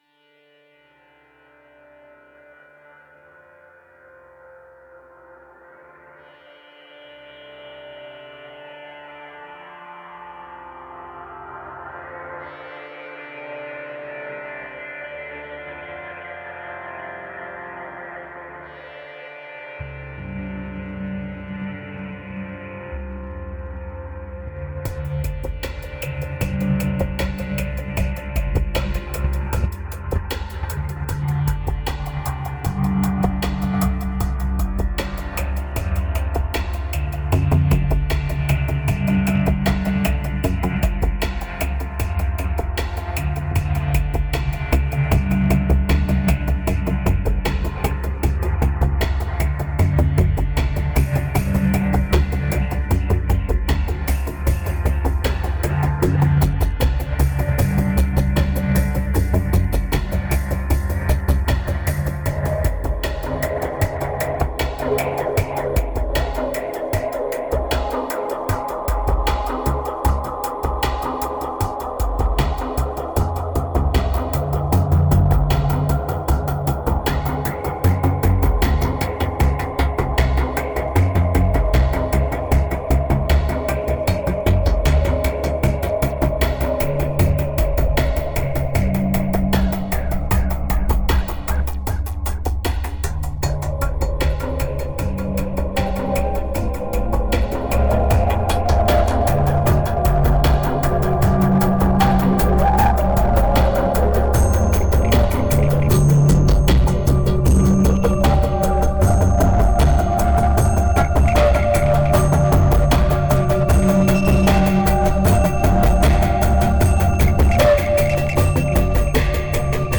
2574📈 - 41%🤔 - 77BPM🔊 - 2012-05-12📅 - 54🌟